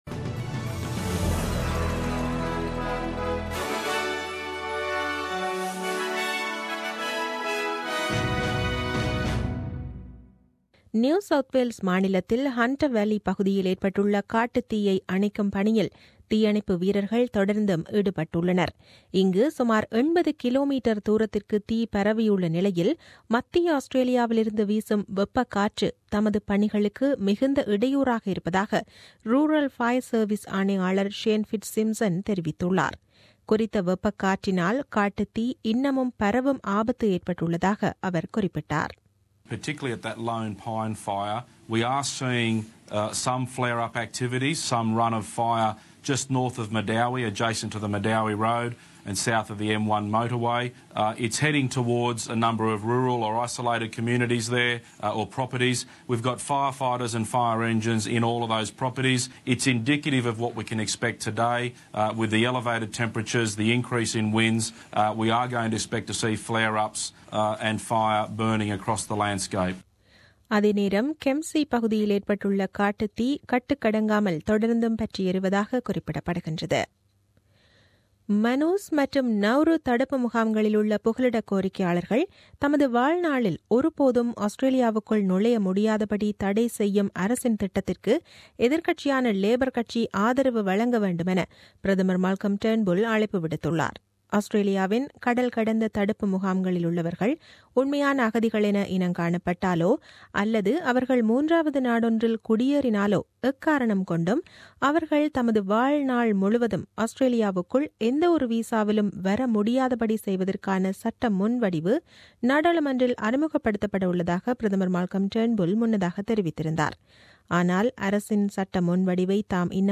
The news bulletin aired on 07 Nov 2016 at 8pm.